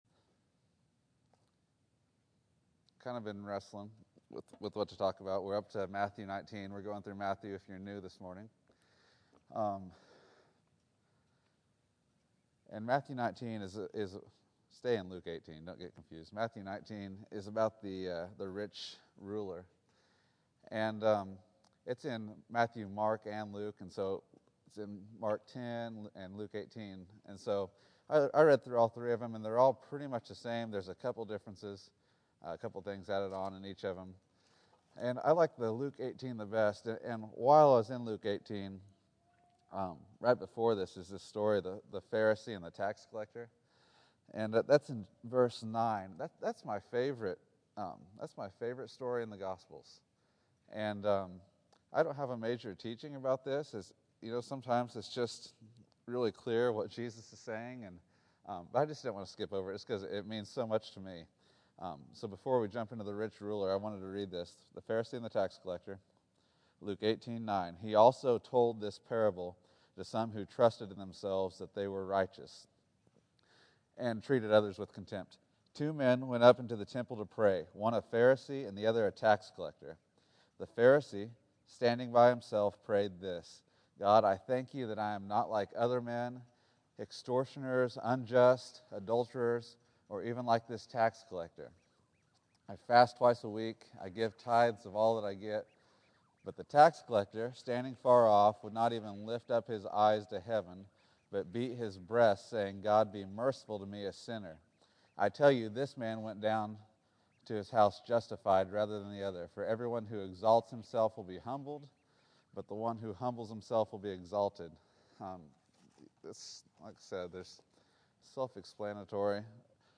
Luke 18 January 19, 2014 Category: Sunday School | Location: El Dorado Back to the Resource Library A rich ruler misses his need for Jesus.